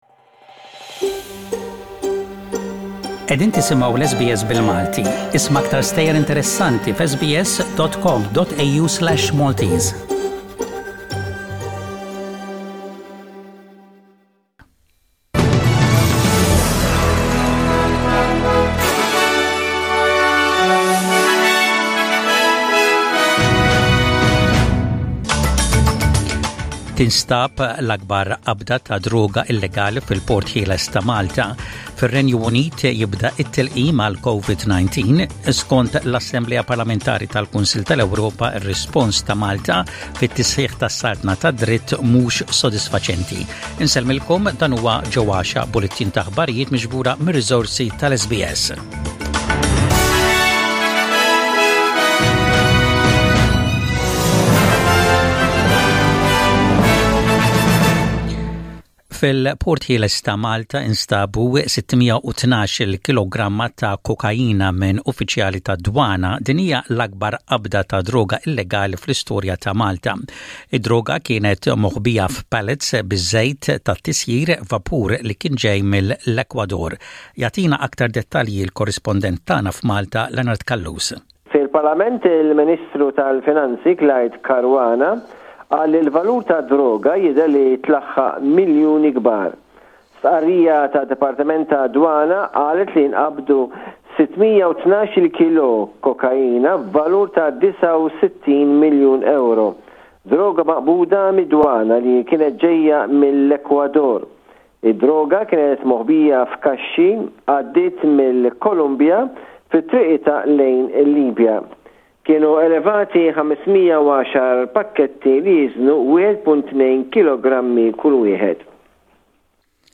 SBS Radio | Aħbarijiet bil-Malti: 11/12/20